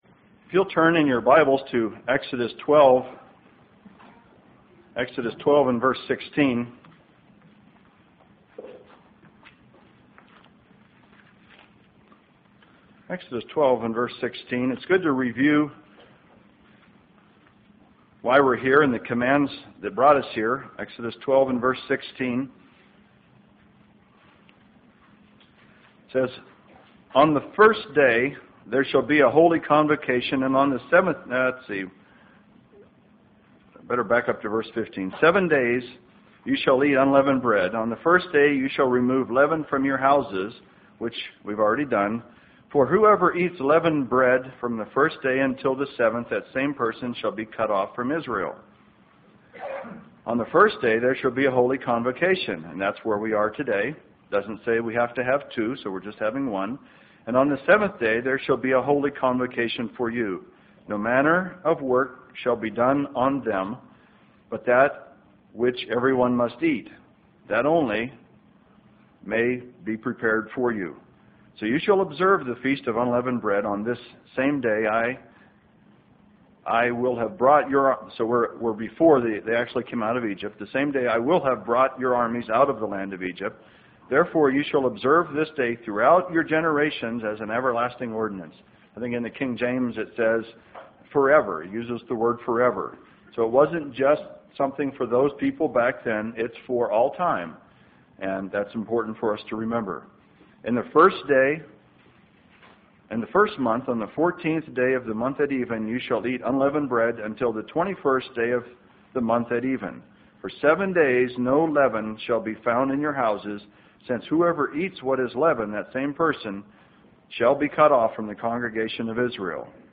Given in Spokane, WA
UCG Sermon Studying the bible?